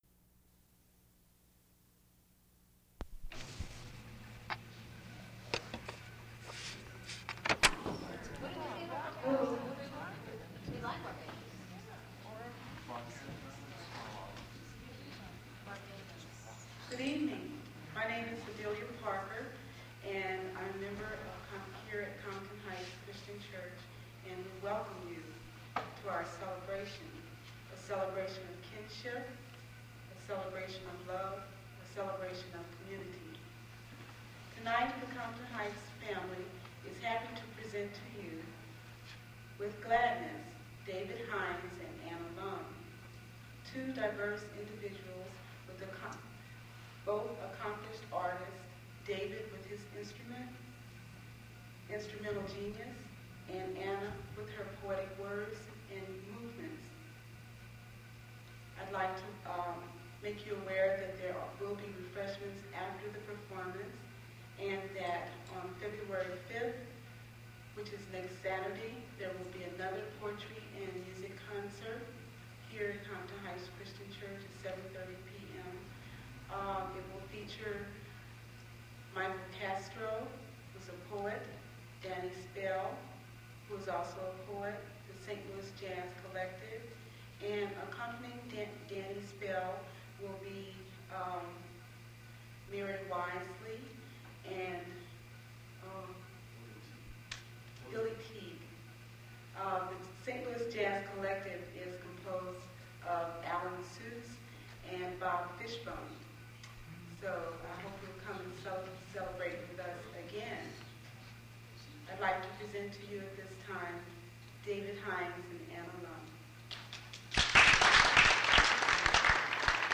mp3 edited access file was created from unedited access file which was sourced from preservation WAV file that was generated from original audio cassette. Language English Identifier CASS.606 Series River Styx at Duff's River Styx Archive (MSS127), 1973-2001 Note The audio quality in this recording isn't great. The applause is a lot louder than the poets speaking.